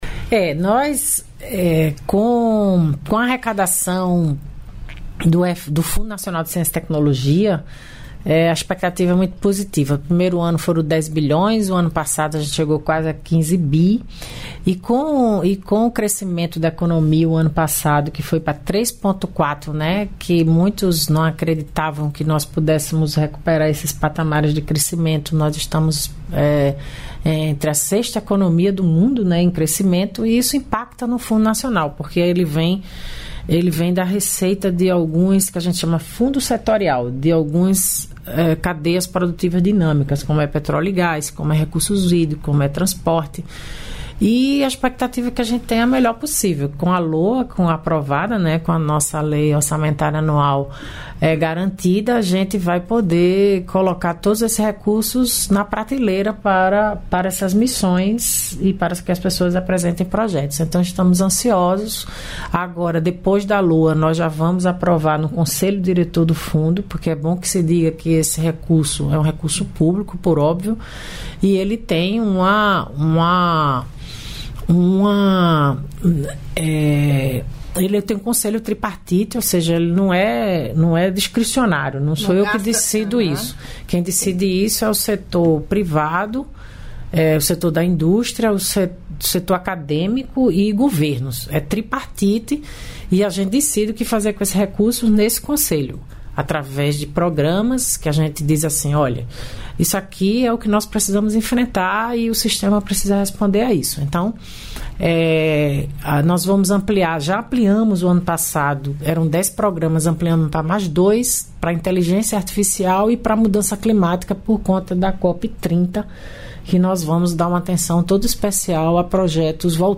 Trecho da participação da Ministra da Ciência, Tecnologia e Inovação, Luciana Santos, nesta quarta-feira (12) no Bom Dia, Ministra, nos estúdios da EBC em Brasília.